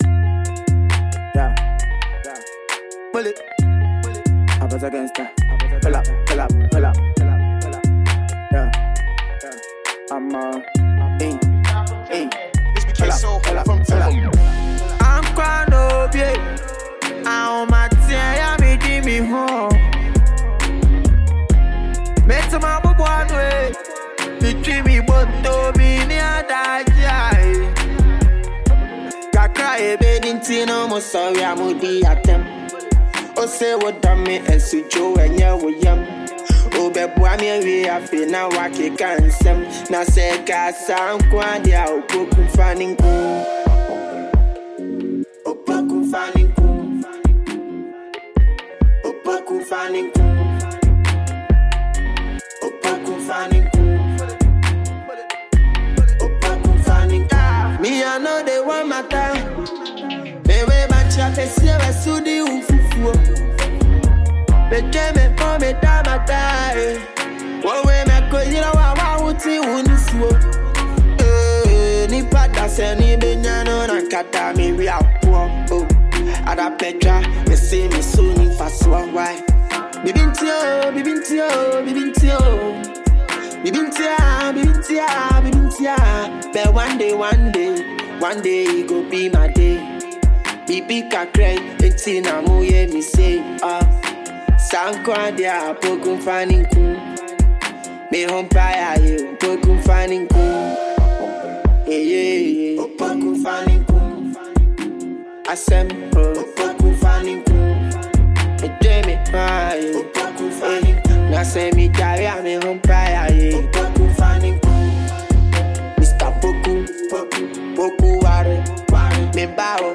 Ghana Music Music
catchy song